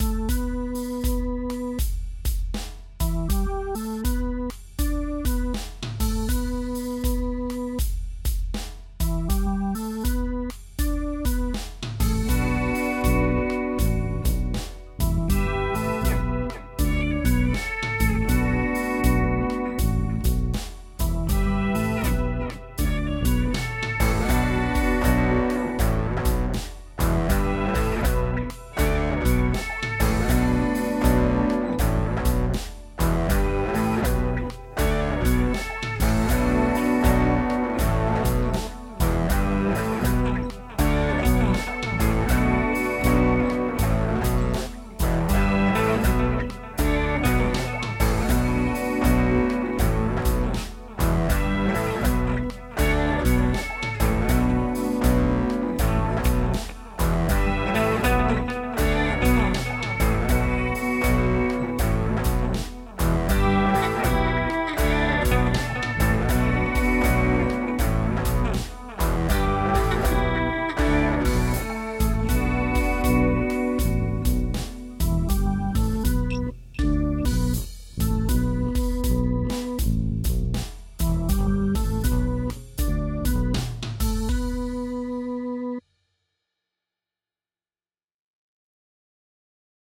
Εἶναι ὁ ρυθμὸς ποὺ τὸ μέτρο του συμπληρώνεται ἐπακριβῶς μὲ μία βραχύχρονη καὶ μιὰ μακρόχρονη ἀξία.
Τοὺς ρυθμοὺς αὐτῆς τῆς ὁμάδας θὰ τοὺς ὀνομάσουμε τριγωνικοὺς δυάρι.